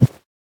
insert_fail.ogg